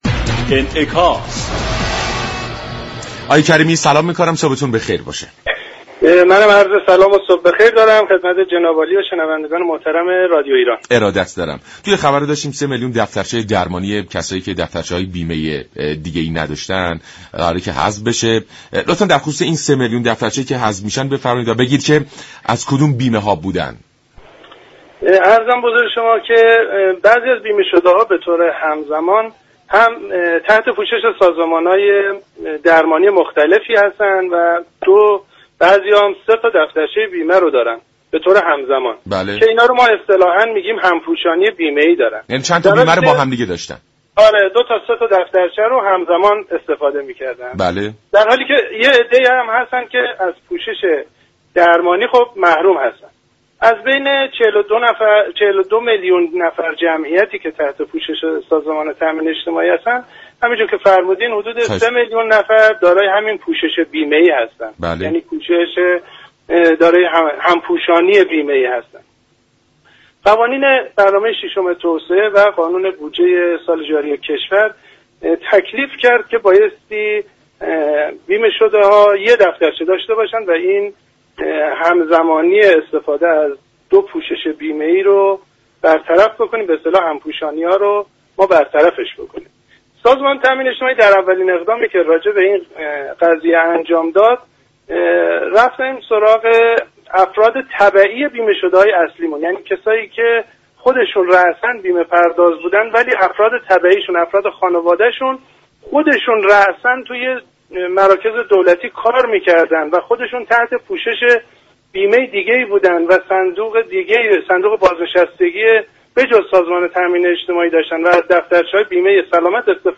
كریمی مدیر كل نام نویسی و حساب های انفرادی سازمان تامین اجتماعی در گفت و گو با رادیو ایران گفت.
این گفت و گو را در ادامه باهم می شنویم.